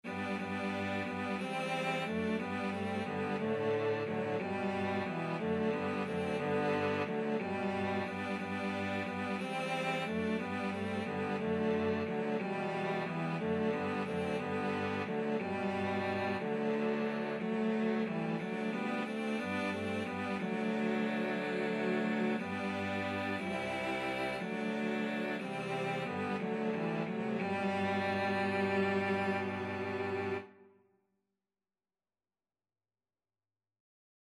Free Sheet music for Cello Quartet
Cello 1Cello 2Cello 3Cello 4
G major (Sounding Pitch) (View more G major Music for Cello Quartet )
3/4 (View more 3/4 Music)
Cello Quartet  (View more Easy Cello Quartet Music)
Traditional (View more Traditional Cello Quartet Music)